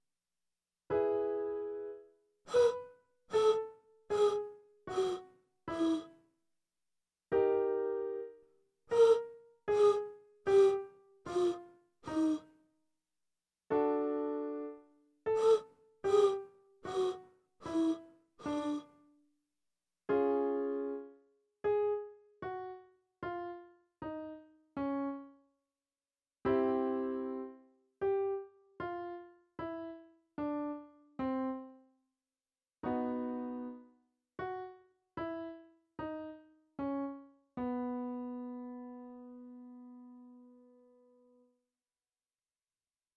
ファルセット／純粋な裏声の練習方法
音量注意！
1. 発音は「フ」
• 「ウ」母音はなるべく暗く虚ろに発音しましょう
2. 音域はB3～B4
• スタートはB4から半音ずつ下降していきます
3. 音量は徐々に小さくなる
practice-falsetto-headvoice-01.mp3